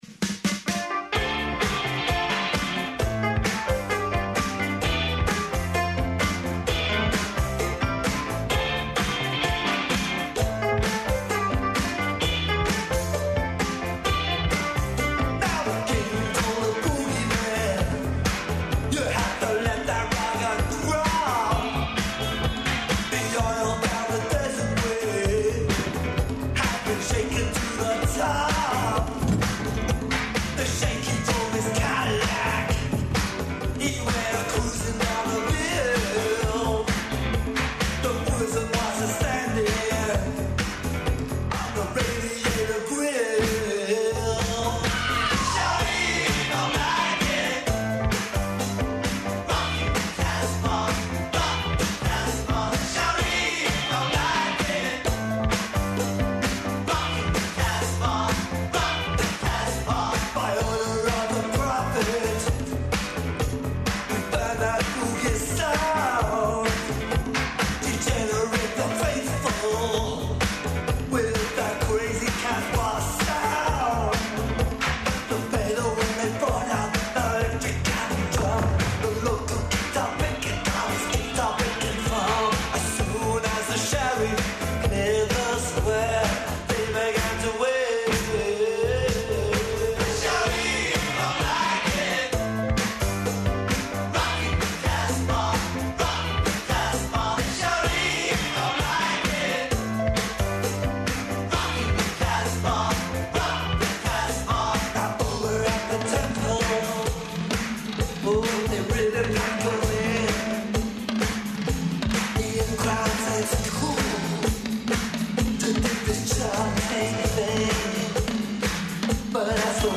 О овим питањима разговарамо са студентима економије и лидерима start-up компанијa, који ће нас - супротно духу конкурентног тржишта - водити путем од идеје до реализације. Извештавамо са јучерашње трибине ‘'Хоризонт образовања и културе у Србији'' где су постављена нека од кључних питања за развој друштва.